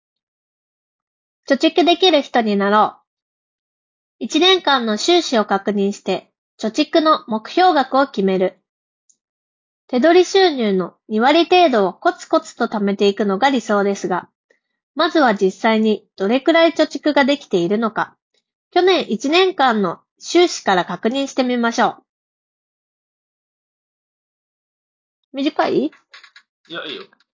こちらのノイキャンも質が高く、周囲のノイズを取り除き、装着者の声のみをクリアに拾い上げることができていた。
さすがは価格相応、もしくは価格以上とも言える、非常にクリアな音声収録が可能であった。
▼Xiaomi Buds 5 Proの内蔵マイクで拾った音声単体
音声を聴いても分かる通り、周囲の環境ノイズ(空調音や屋外の音)を効果的に除去し、発言内容を明瞭に拾い上げることができている。
xiaomi-buds-5-pro-review.mp3